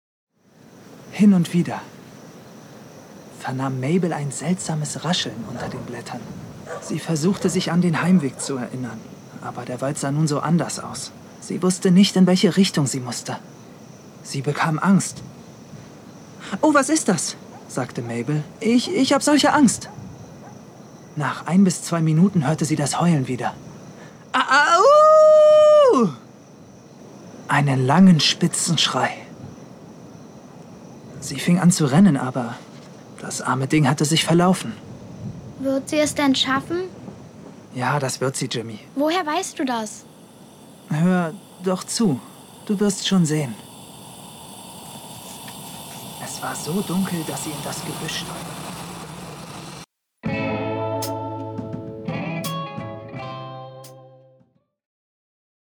Stimmproben